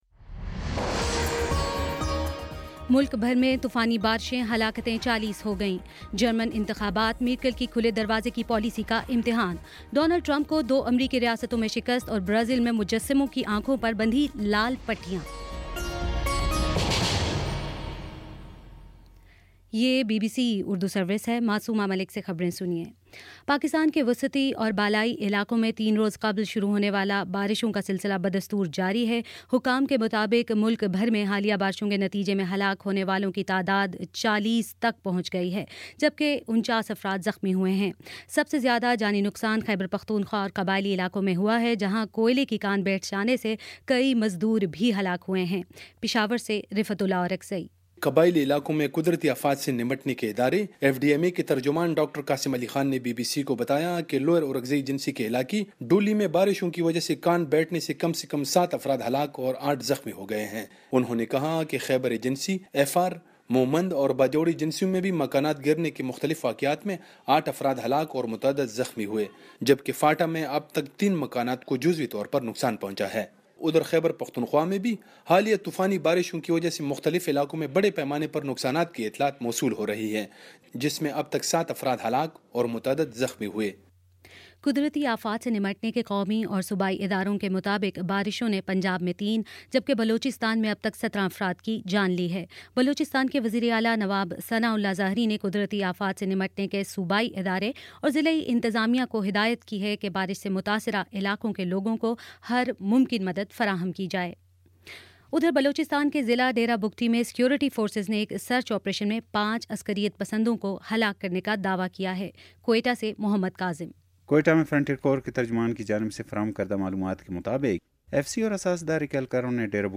مارچ 13 : شام پانچ بجے کا نیوز بُلیٹن